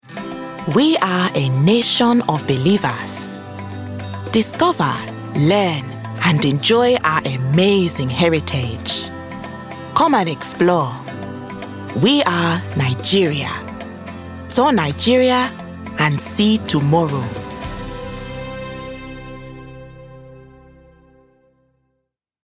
Nigerian Accent Showreel
Female
Bright
Friendly